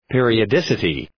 Προφορά
{,pıərıə’dısətı}
periodicity.mp3